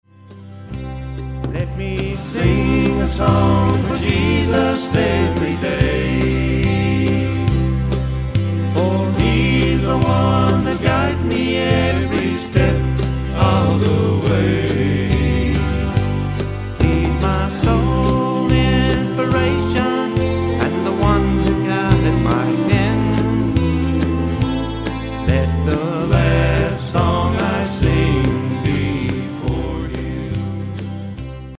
Autoharp, Lead & Harmony Vocals
Fiddle